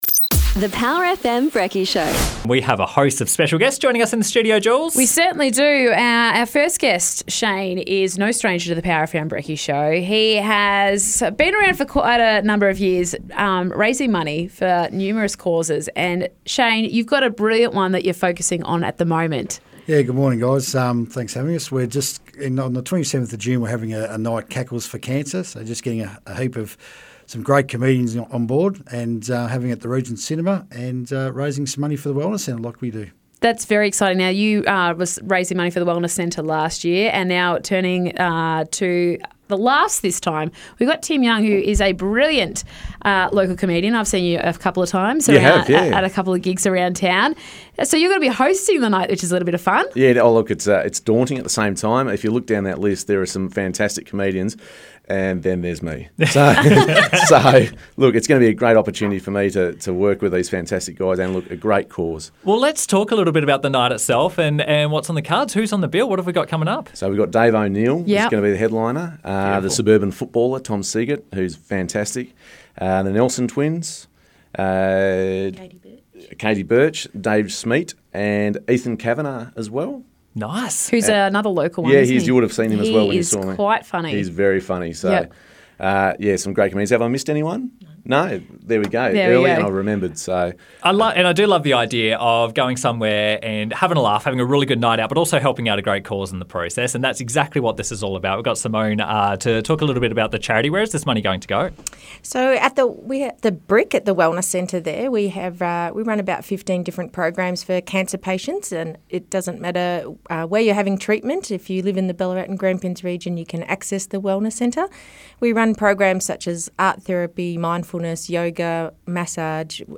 Cackles for Cancer Interview